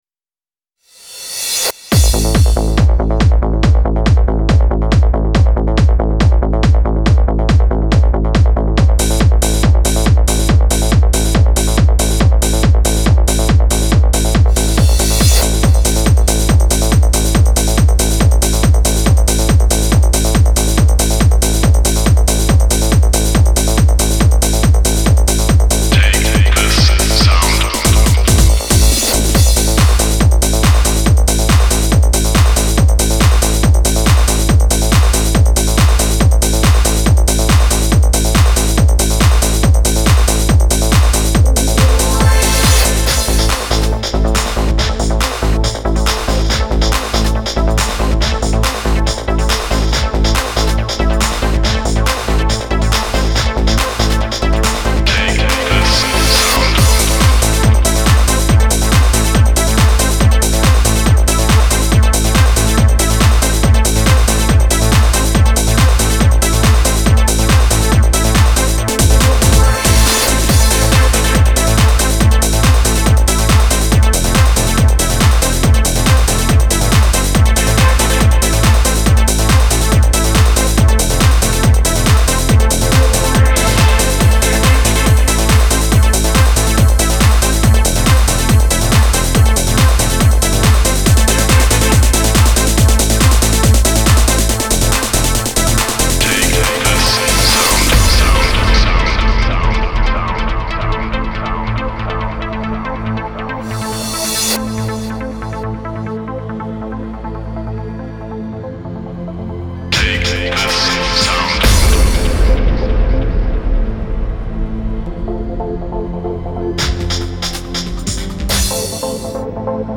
Психоделик евродэнс